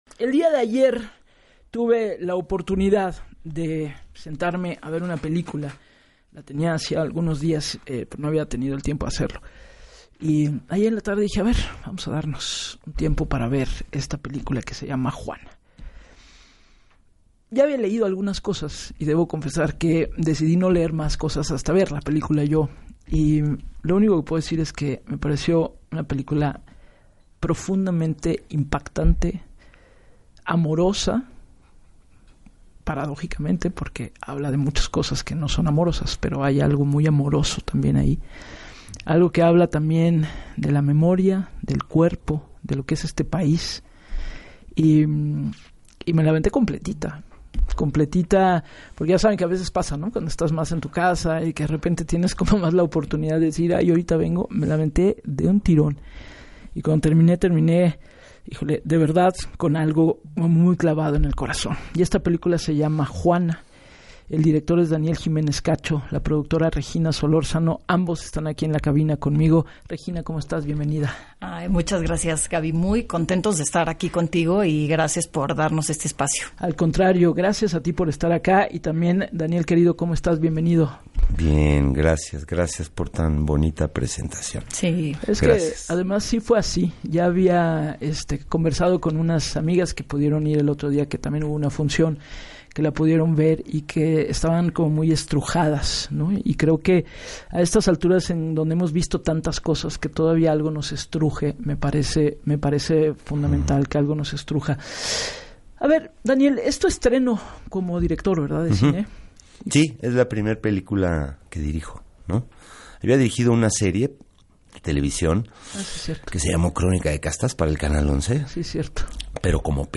Daniel Giménez Cacho, actor y director